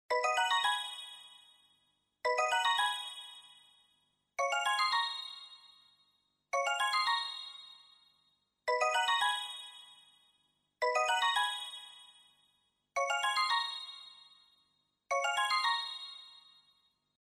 Timer.mp3